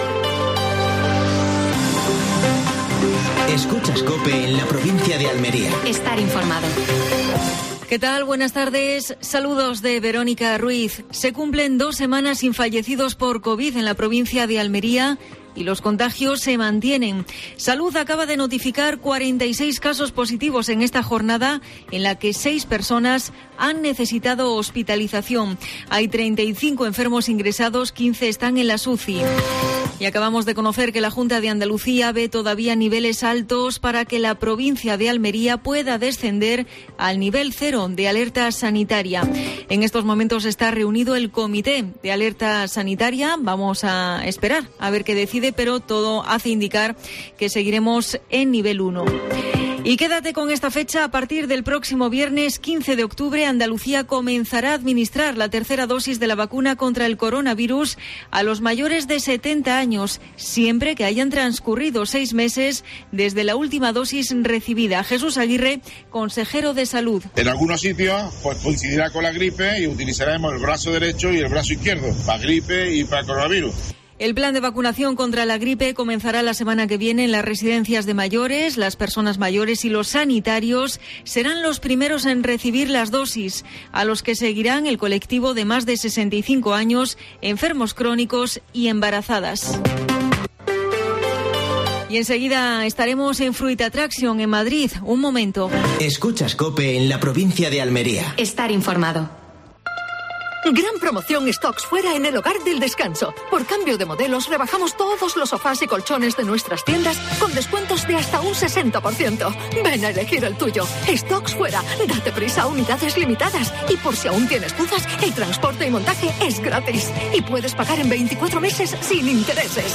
AUDIO: Última hora en Almería. Actualidad en Fruit Attraction. Entrevista